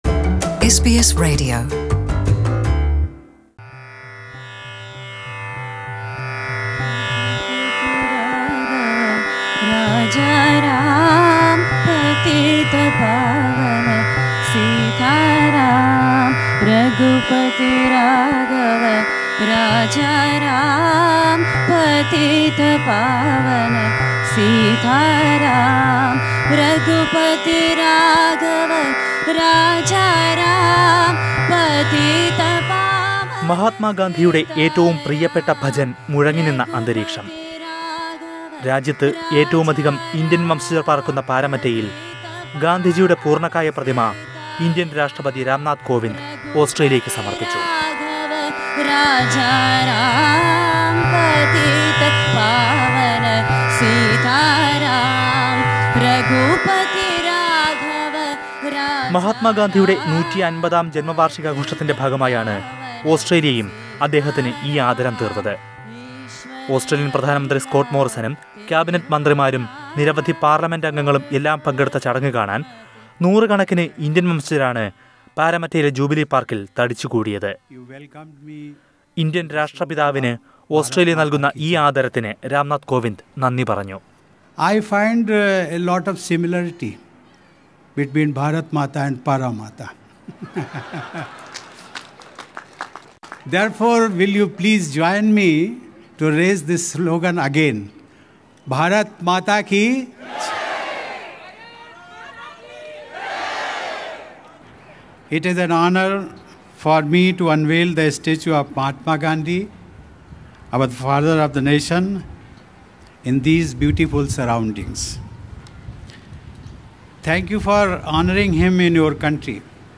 The President of India Ram Nath Kovind unveils Mahatma Gandhi's statue at Paramatta in Sydney during his four day long visit to Australia. Australian Prime Minister Scott Morrison was also present at the event. Listen to a report on this.